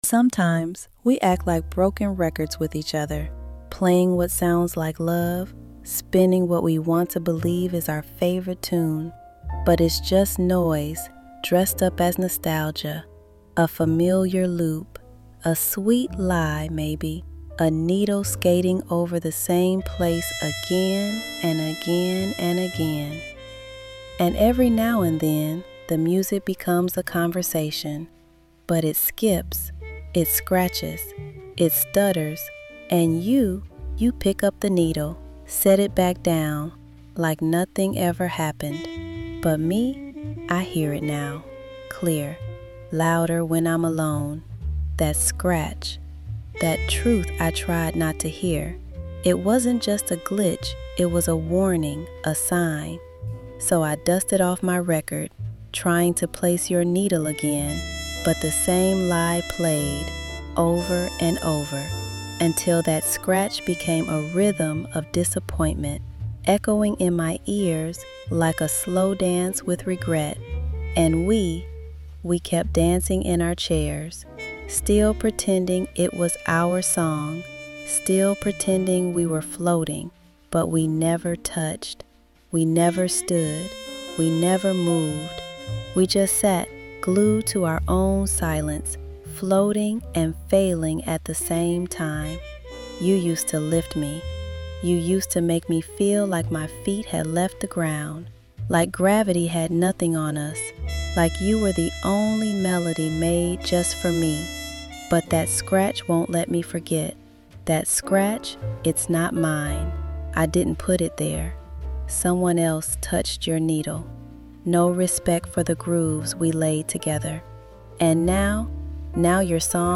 Each audio file invites you to see without seeing, painting vivid portraits and unfolding unseen moments using only sound.